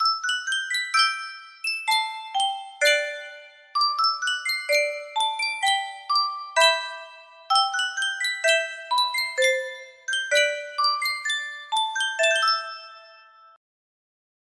Sankyo Music Box - SAN CBT music box melody
Full range 60